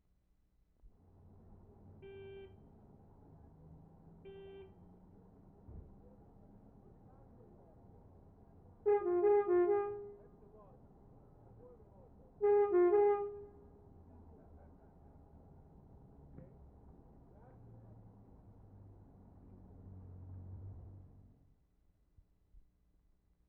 Cembra, Italy April 1,2/75
MOUNTAIN BUS HORN
9. Minor third of bus horn. Special horns used in Italy by mountain buses to obtain right away on narrow roads.